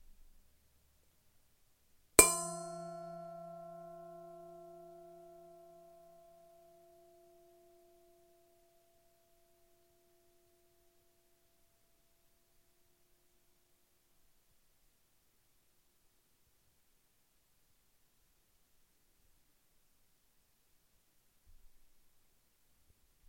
标准钟
描述：用金属片敲击KitchenAid不锈钢搅拌碗。原始声音。使用StudioSix Digital iAudioInterface2和iPhone 5S以及Røde NTG2进行录音
Tag: 厨房